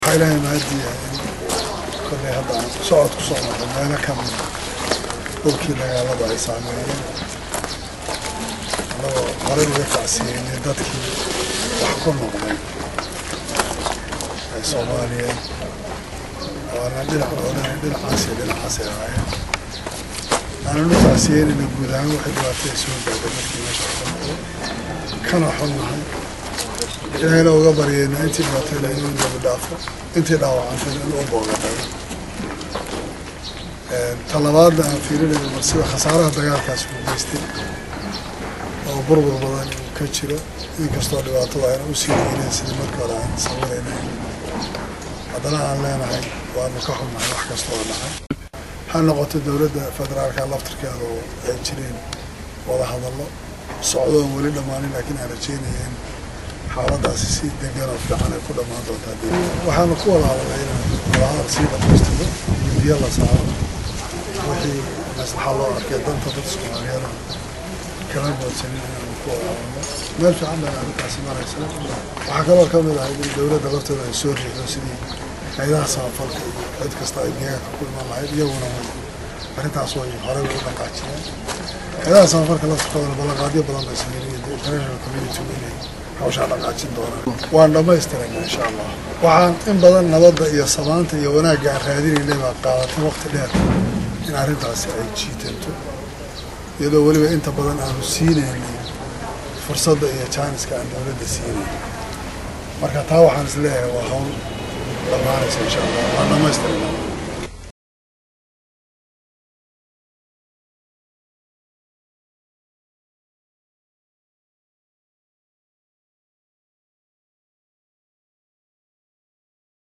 Madaxweynaha ayaa hadal uu saxafada siiyay waxa uu ka hadalay kormeerkiisa manta uu ku marayo qeebo ka mid ah magalada Kismayo, asagoo u tacsiyeyay dhamaan dadkii ku waxyeeloobay dagaalkii dhawaan dhacay.